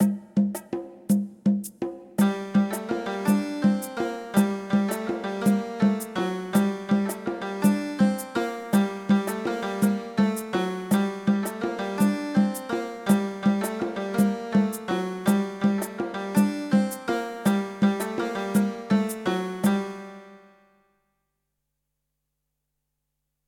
Música ambiental del cuento: El príncipe serpiente
ambiente
melodía
sintonía